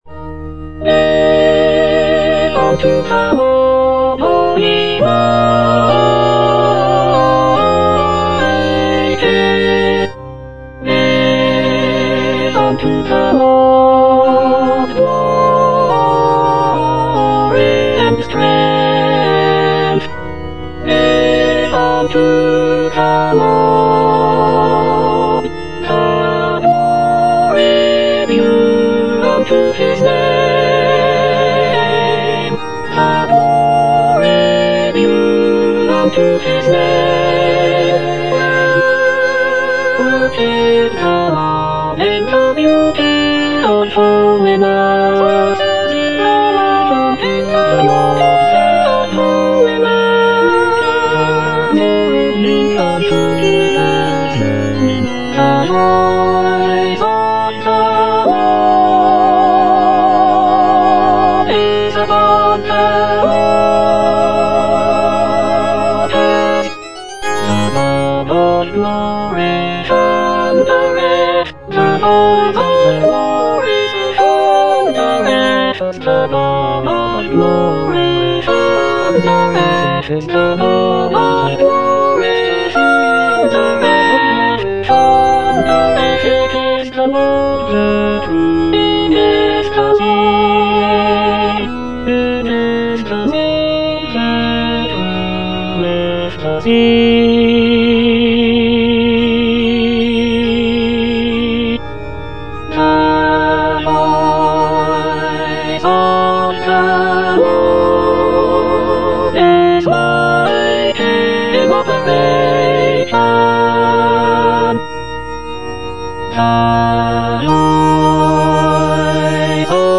E. ELGAR - GIVE UNTO THE LORD Soprano I (Emphasised voice and other voices) Ads stop: auto-stop